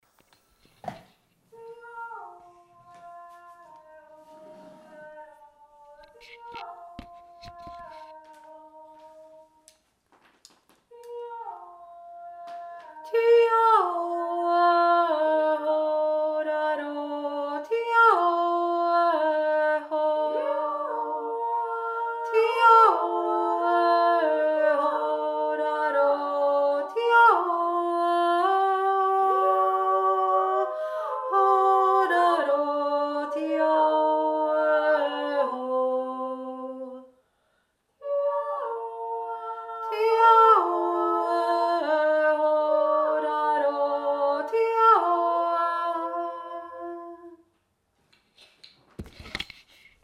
alle drei
feenjodler-alle-drei-stimmen.mp3